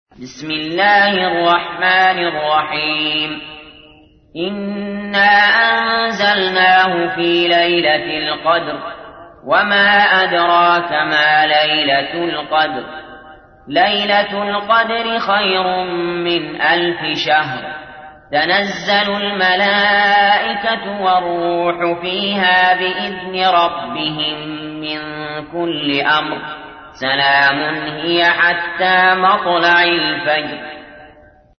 تحميل : 97. سورة القدر / القارئ علي جابر / القرآن الكريم / موقع يا حسين